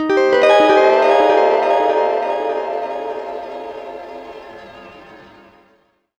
GUITARFX15-R.wav